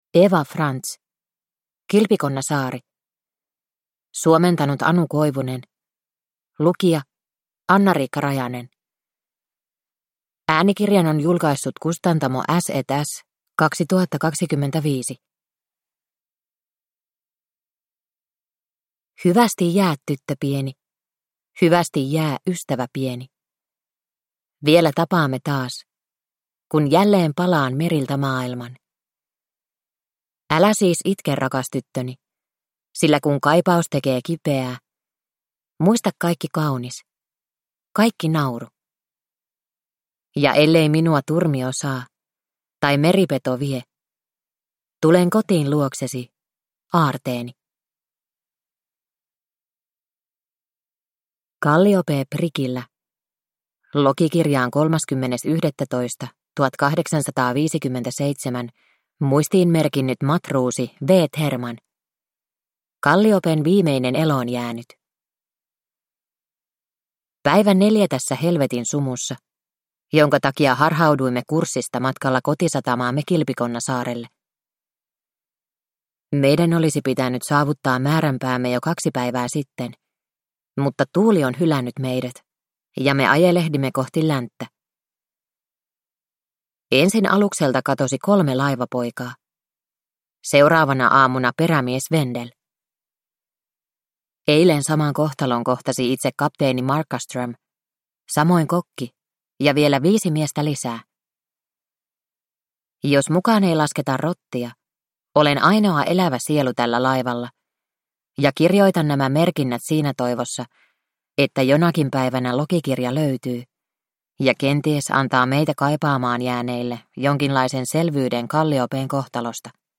Kilpikonnasaari – Ljudbok